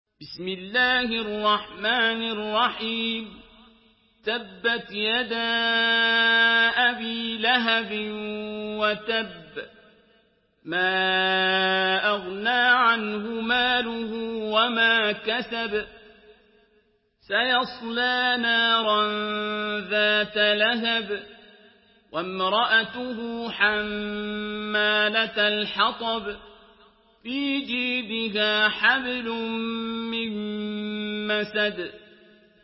Surah Al-Masad MP3 by Abdul Basit Abd Alsamad in Hafs An Asim narration.
Murattal Hafs An Asim